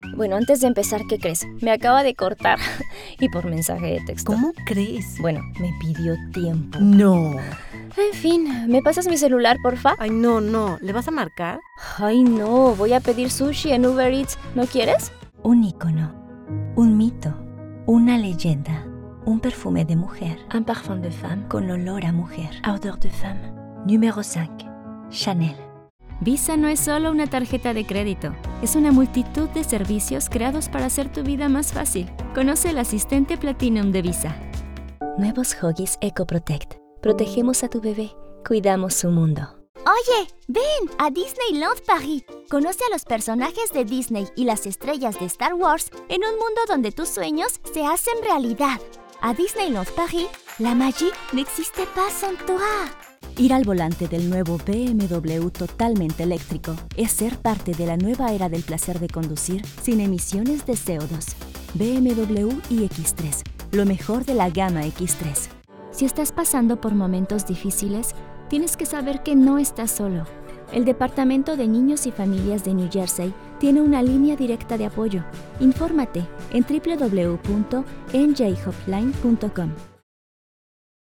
Commercial Demo - Latin American Neutral Spanish